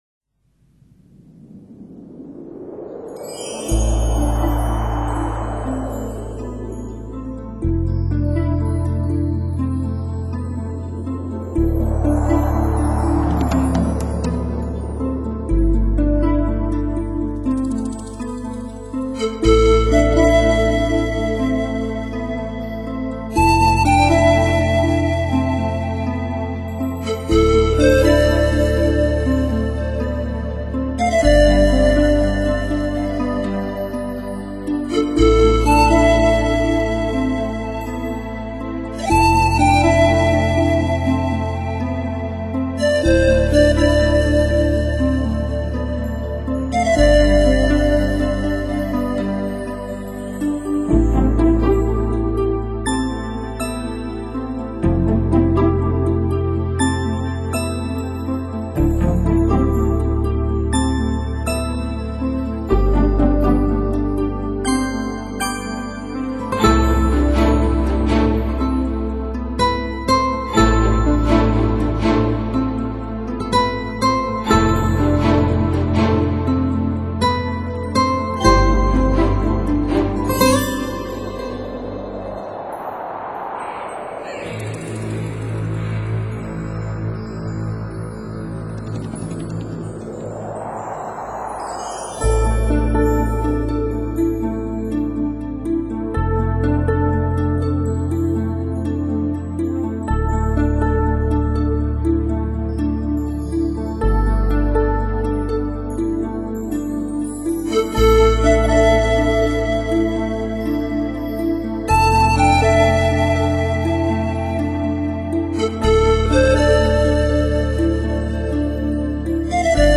新世纪
★细腻的动态清晰可监
★山林湖面的辽阔与回音
空灵飘渺的音乐世界